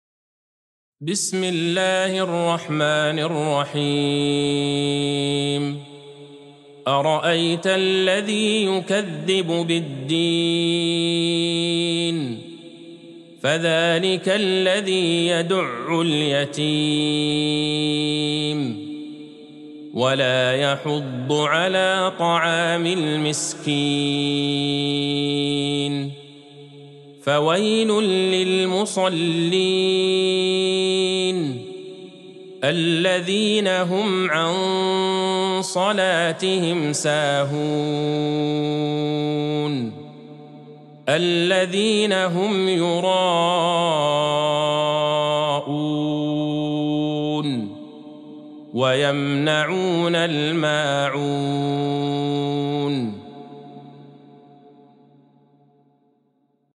سورة الماعون Surat Al-Maun | مصحف المقارئ القرآنية > الختمة المرتلة ( مصحف المقارئ القرآنية) للشيخ عبدالله البعيجان > المصحف - تلاوات الحرمين